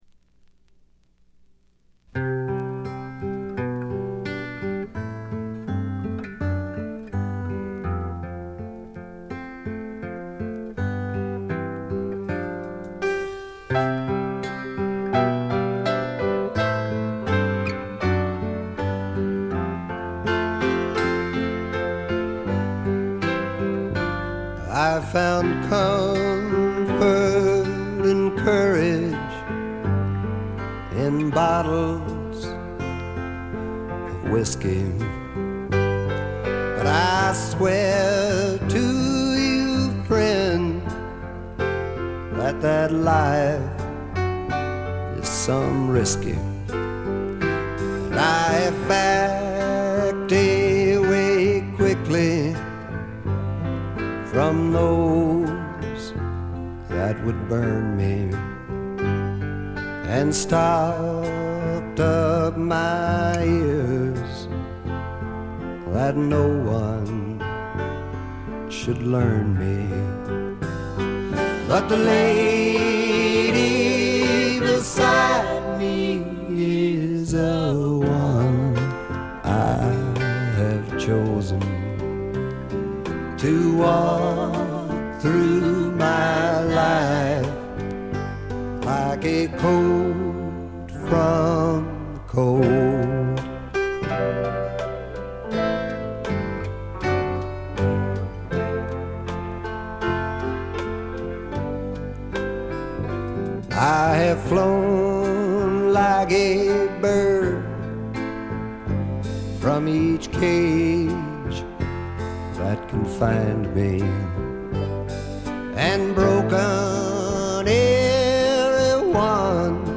軽微なチリプチ少々。
朴訥な歌い方なのに声に物凄い深さがある感じ。
試聴曲は現品からの取り込み音源です。